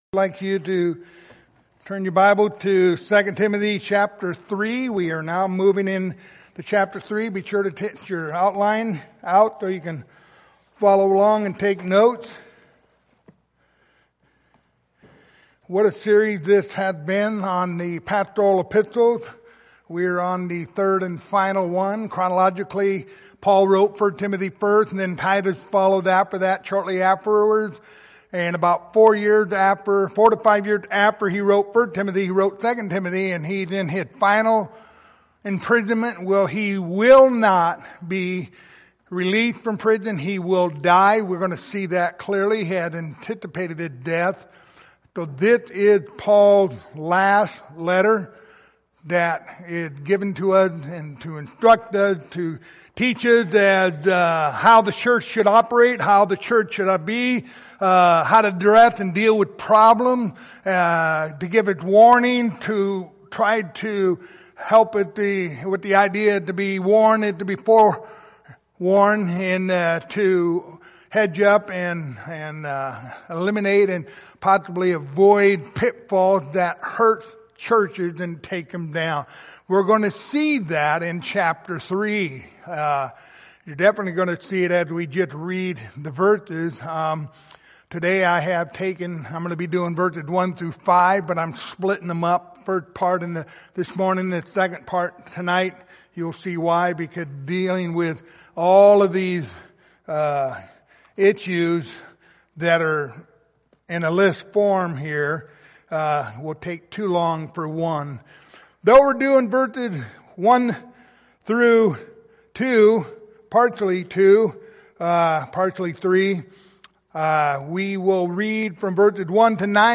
Passage: 2 Timothy 3:1-5 Service Type: Sunday Morning